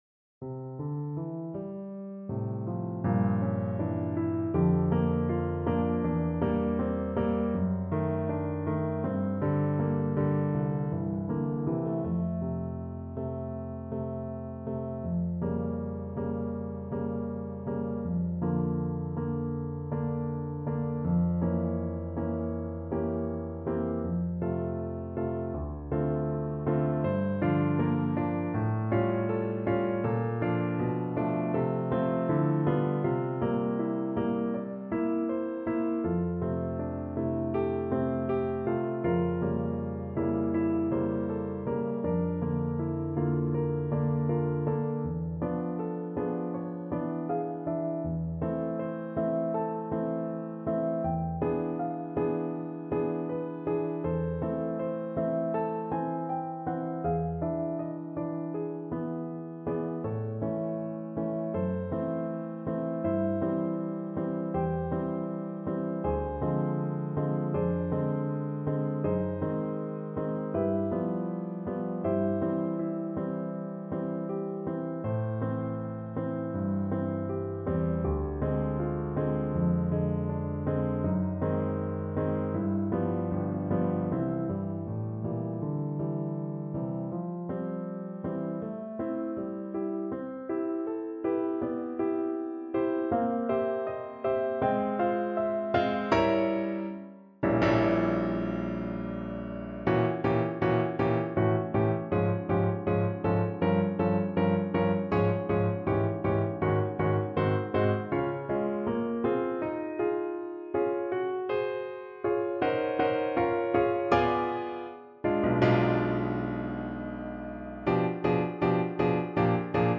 Andante espressivo
4/4 (View more 4/4 Music)
F4-Bb6
Classical (View more Classical Trumpet Music)